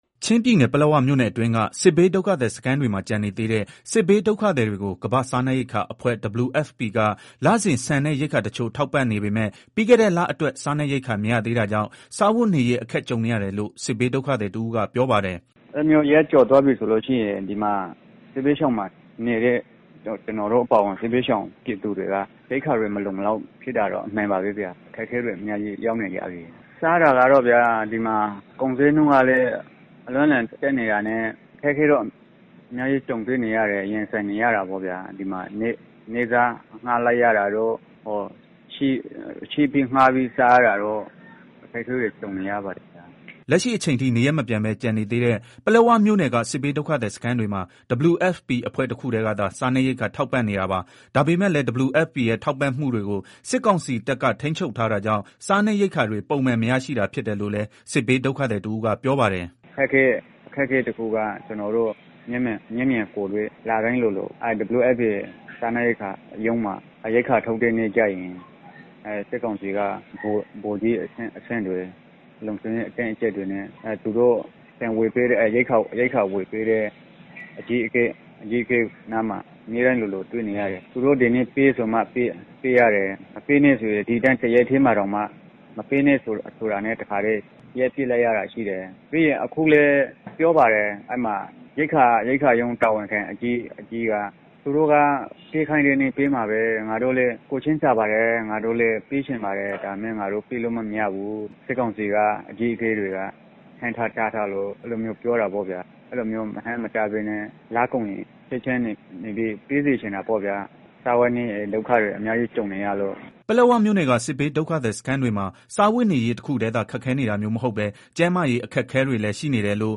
ချင်းပြည်နယ် ပလက်ဝမြို့နယ်အတွင်းက စစ်ဘေးဒုက္ခသည်စခန်းတွေမှာ ကျန်နေသေးတဲ့ စစ်ဘေးဒုက္ခသည်တွေကို ကမ္ဘာ့စားနပ်ရိက္ခာအဖွဲ့ WFP က လစဉ် ဆန်နဲ့ ရိက္ခာတချို့ ထောက်ပံ့နေပေမဲ့ ပြီးခဲ့တဲ့ လအတွက် စားနပ်ရိက္ခာမရသေးတာကြောင့် စားဝတ်နေရေး အခက်ကြုံနေရတယ်လို့ စစ်ဘေးဒုက္ခသည်တဦးကပြောပါတယ်။
ပလက်ဝမြို့နယ်က စစ်ဘေးဒုက္ခသည်စခန်းတွေမှာ စားဝတ်နေရေးတခုတည်းသာ ခက်ခဲနေတာမျိုးမဟုတ်ပဲကျန်းမာရေးအခက်အခဲတွေလည်း ရှိနေတယ်လို့လည်း စစ်ဘေးဒုက္ခသည်အမျိုးသမီးတဦးကပြောပါတယ်။